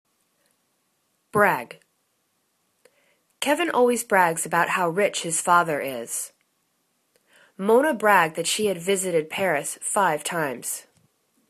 brag     /brag/    v